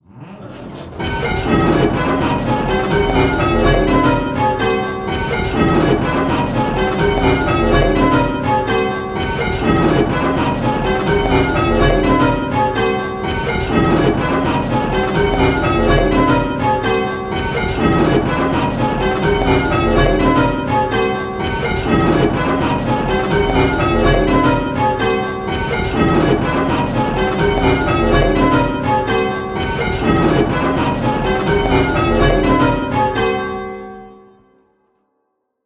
Striking 8 Bell Rounds - Pebworth Bells
Striking 8 Bell Rounds - Round 1